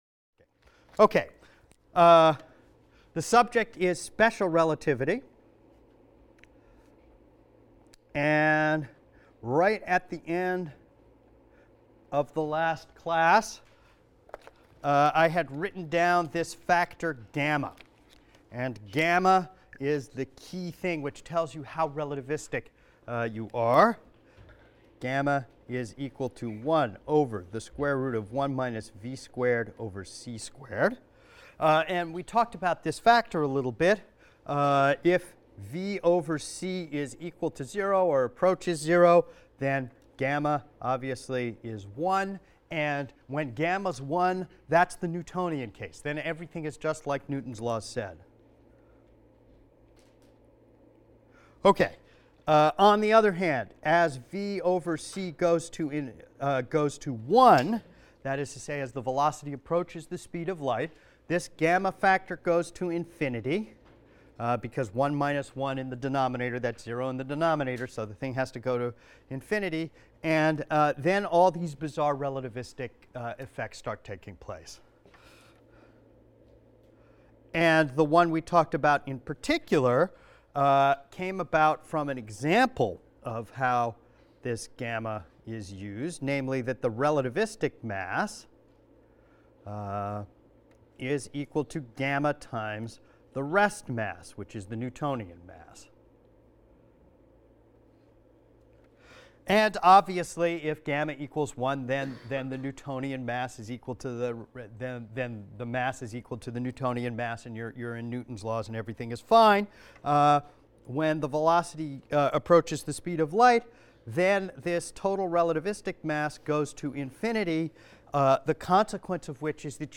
ASTR 160 - Lecture 10 - Tests of Relativity | Open Yale Courses